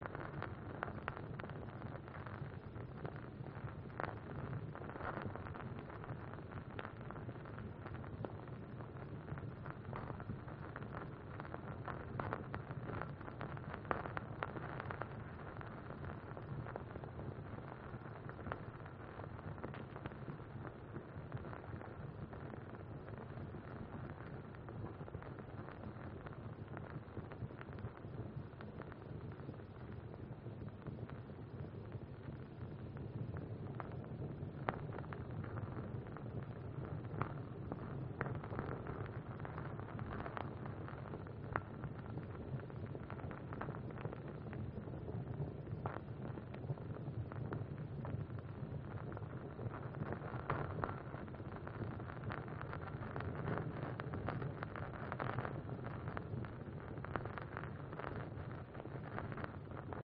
Пылающая керосиновая лампа